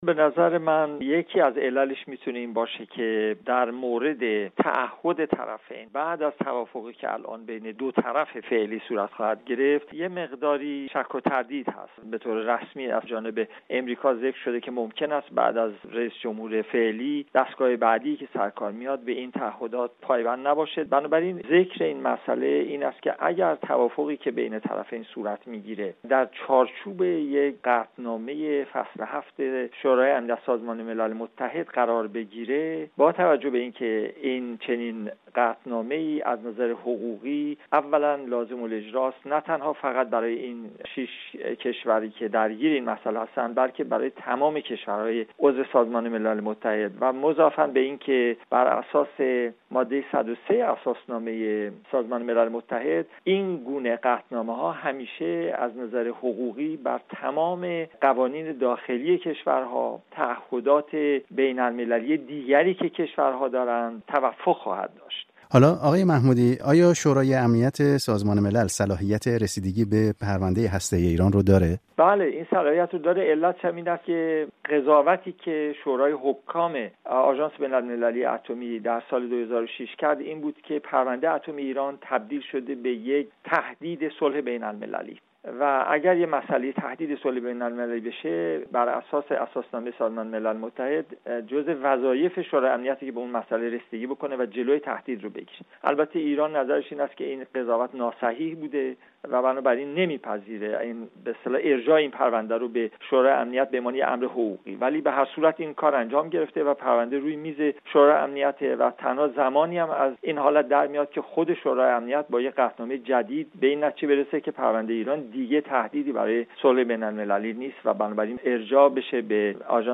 گفت وگوی